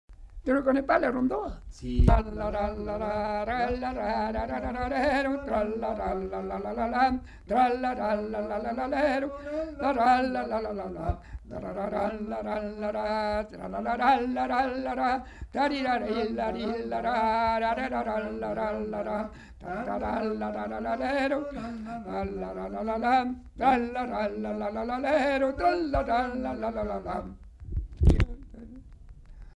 Aire culturelle : Savès
Genre : chant
Effectif : 1
Type de voix : voix d'homme
Production du son : fredonné
Danse : rondeau